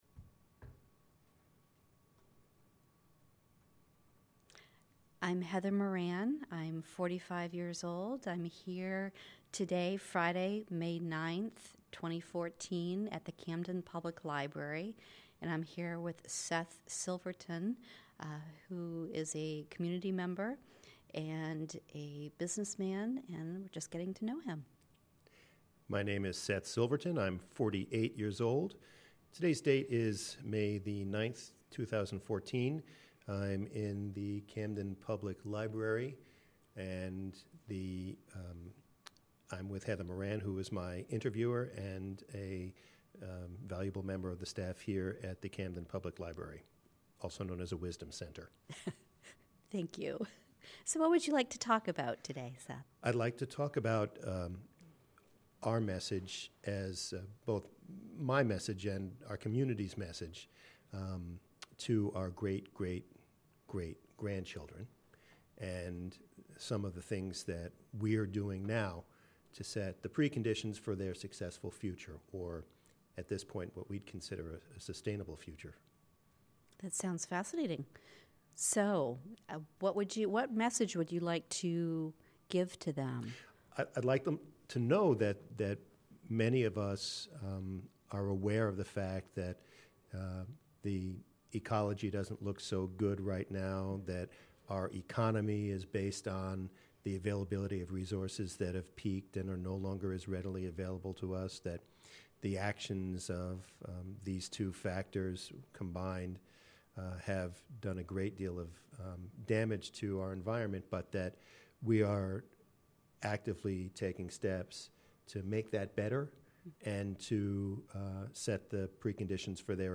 Oral Histories and StoryCorps | Camden Public Library